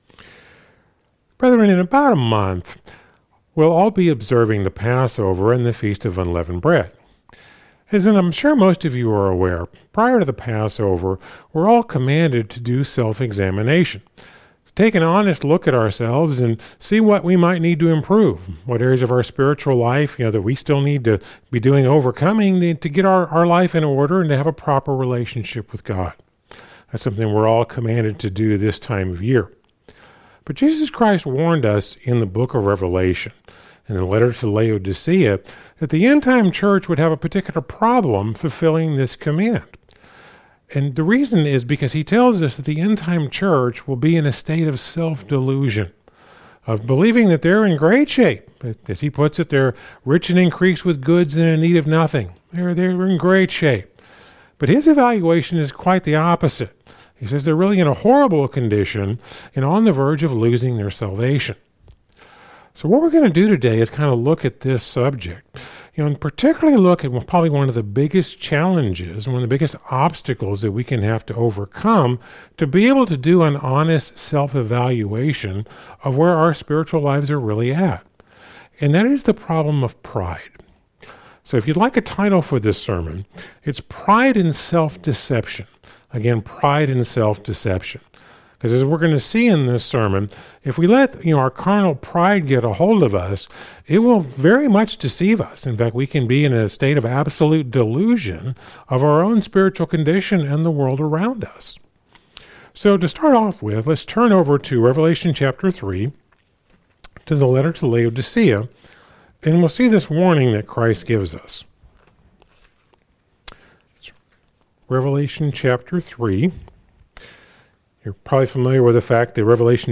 Sermons – Searching The Scriptures